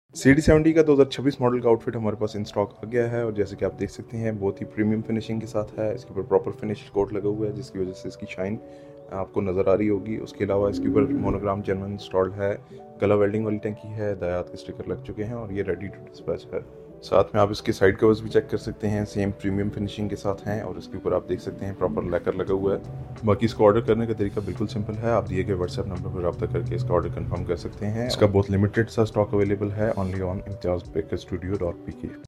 Honda CD 70 2026 Model sound effects free download